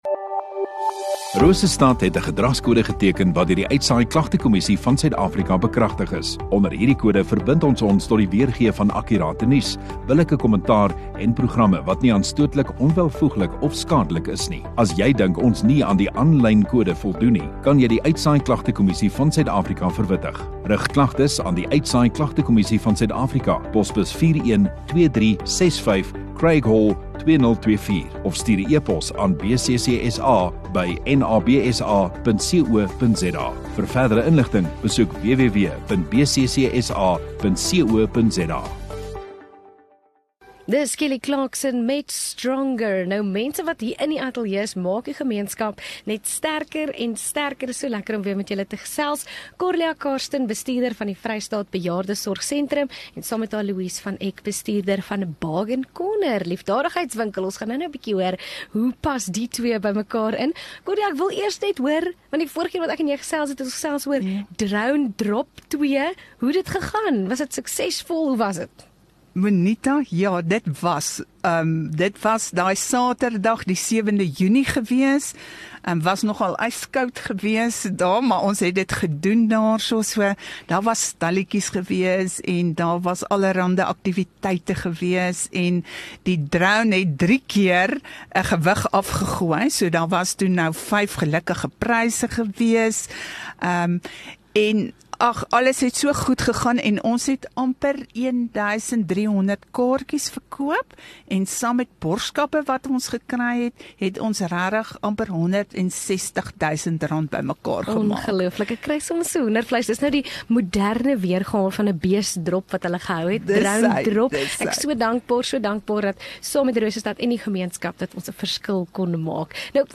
Gemeenskap Onderhoude